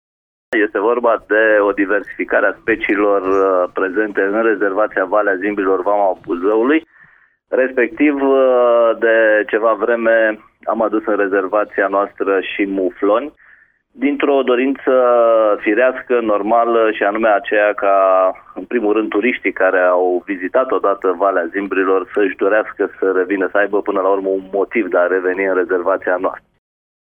Primarul comunei Valea Buzăului, Tiberiu Chirilaş: